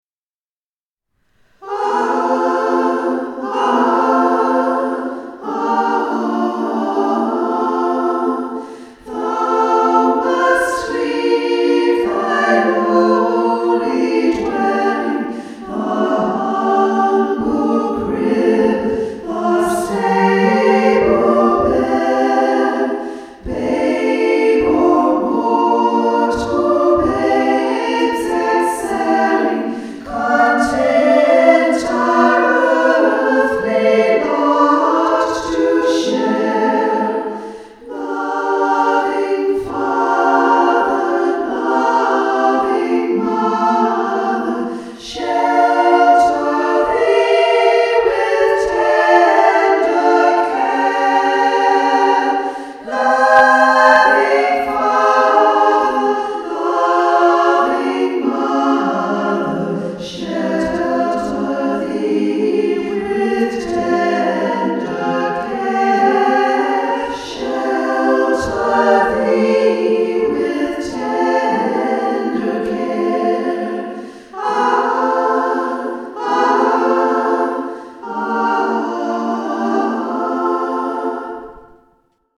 SSAA A Cappella
Anthems: Advent / Christmas
A CAPPELLA arrangement for women's voices (SSAA)